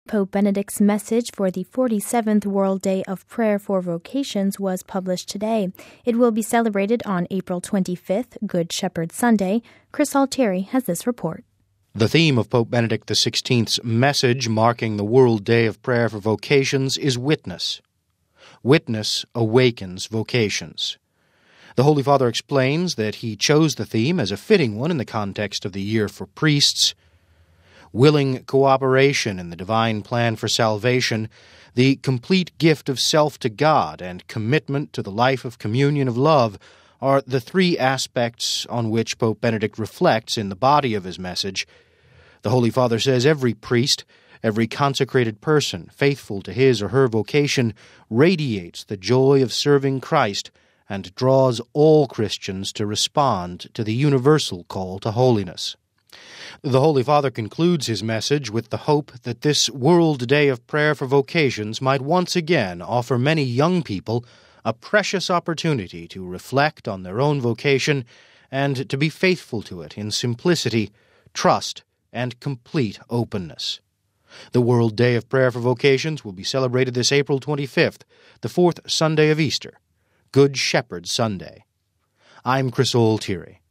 We have this report: